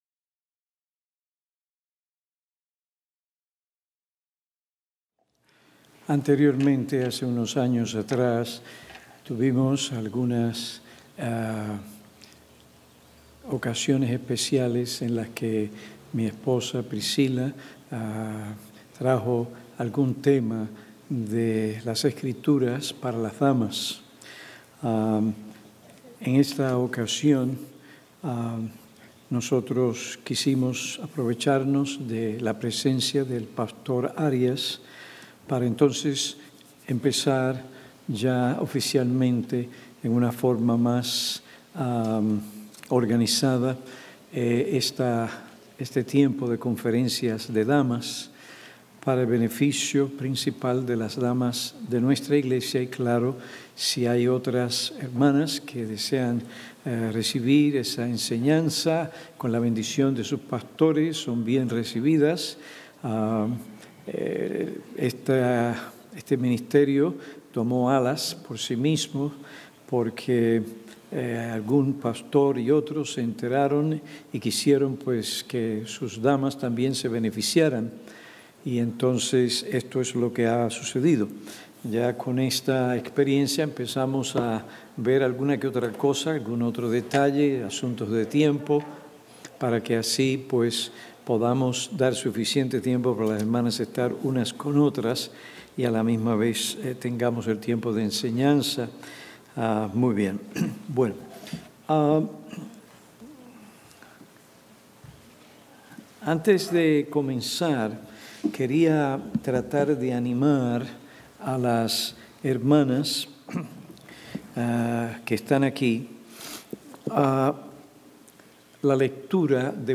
Conferencia de damas 2021 | Preguntas y respuestas – Conferencia Pastoral